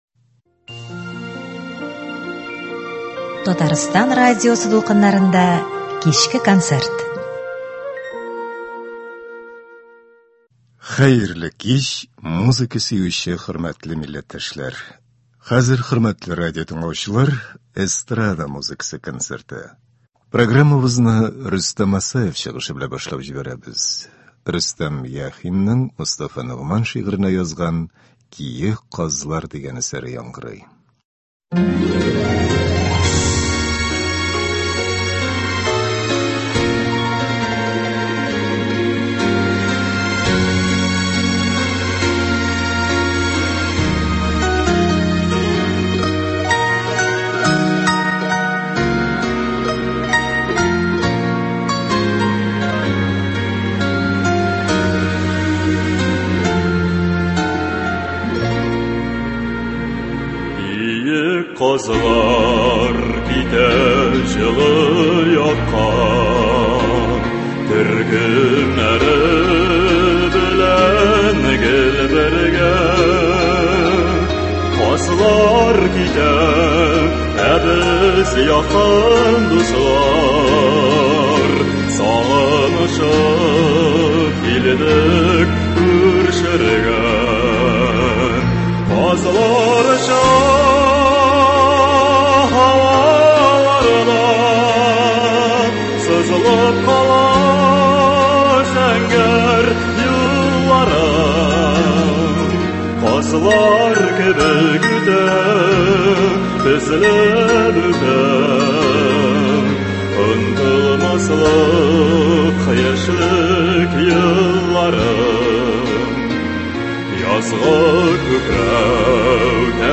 Эстрада концерты.